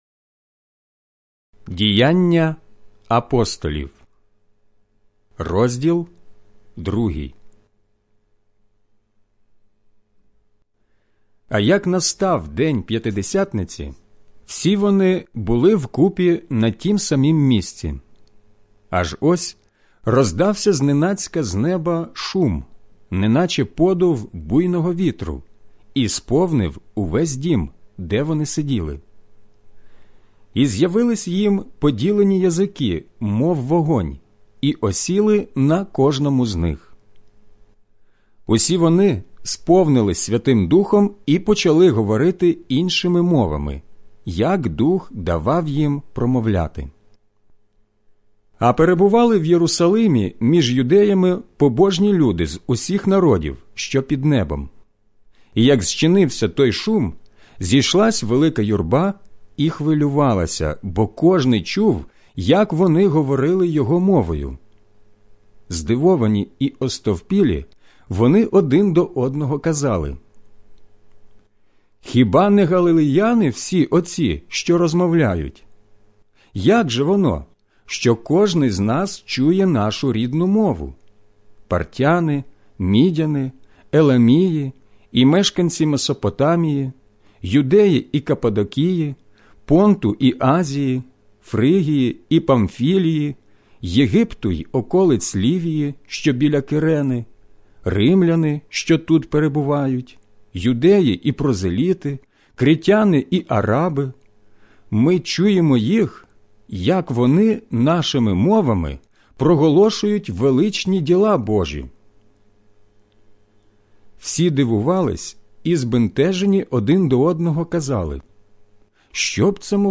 аудіобіблія Діяння Апостолів